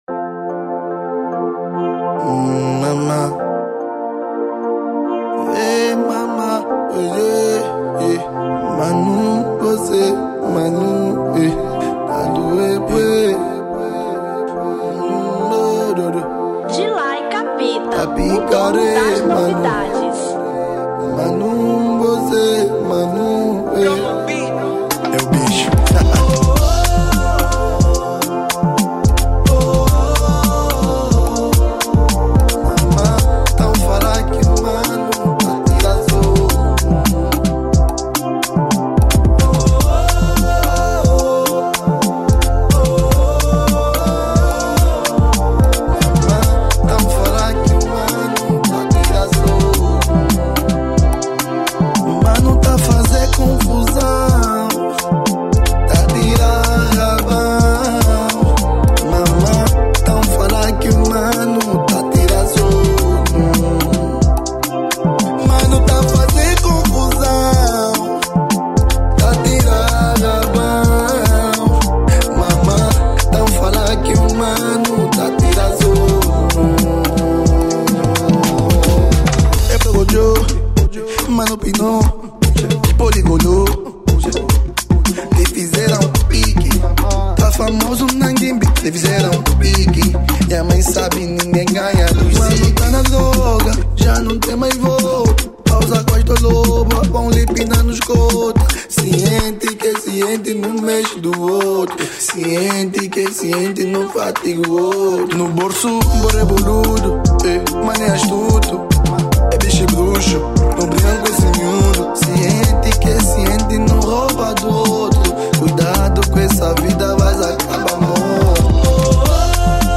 Kuduro 2025